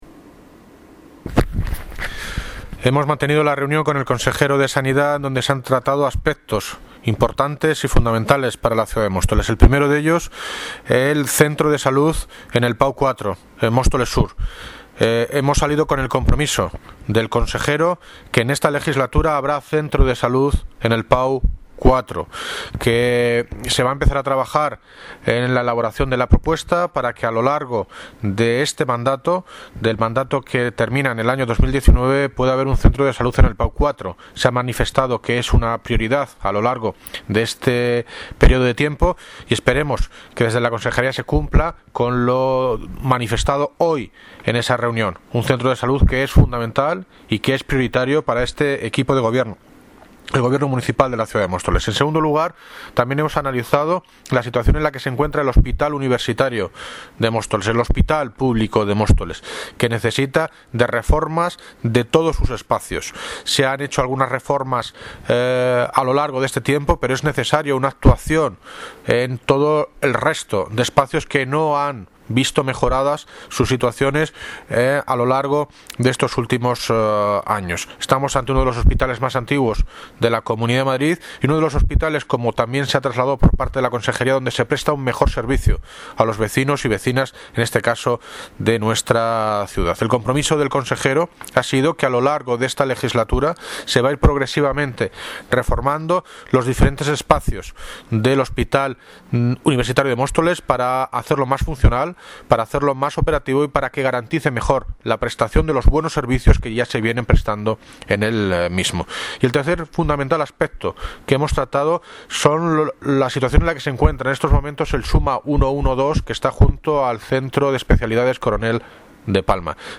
Audio - David Lucas (Alcalde de Móstoles) Sobre reunión Consejero de Sanidad